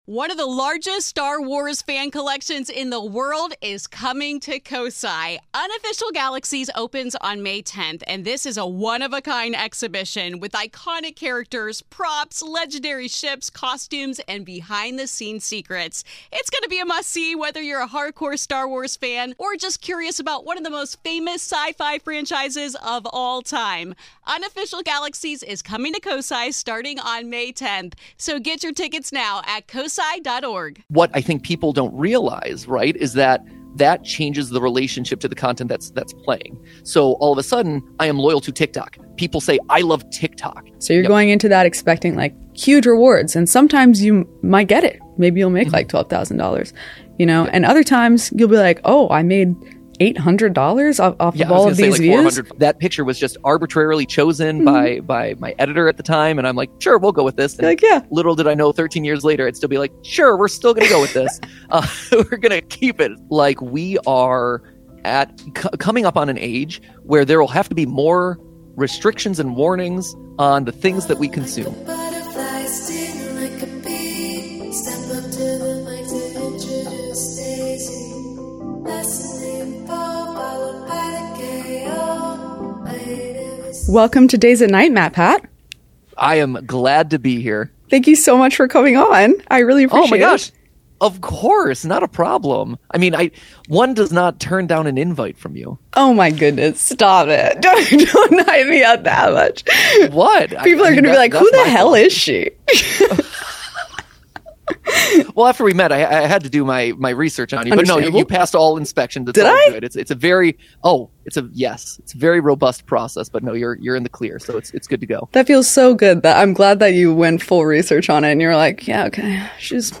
interviews MattPat on the current state of digital media industry, platform regulation, and life in semi-retirement